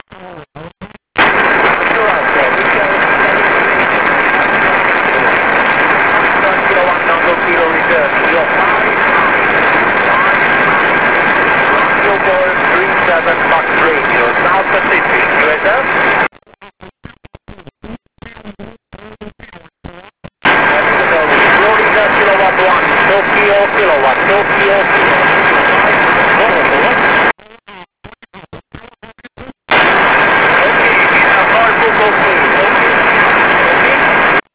Hear His Signal in Europe!